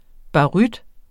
Udtale [ bɑˈʁyd ]